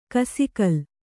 ♪ kasikal